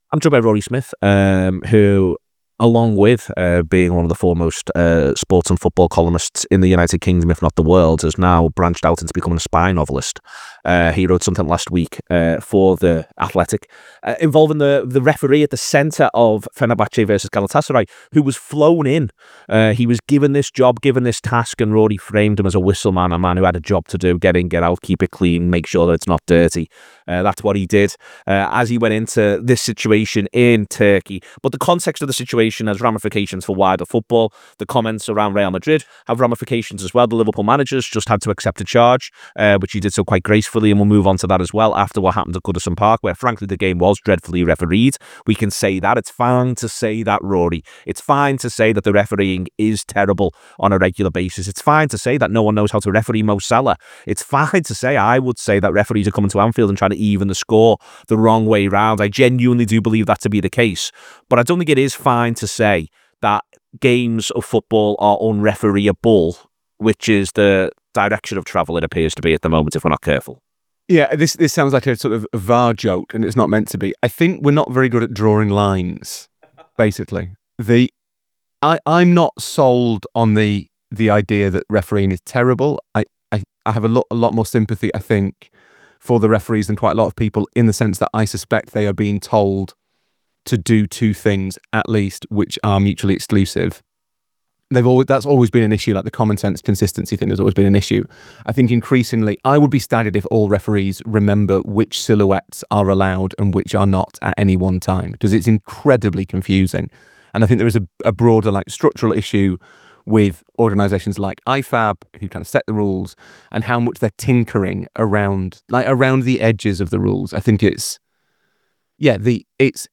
Below is a clip from the show – subscribe for more deep dives on the issues currently affecting football…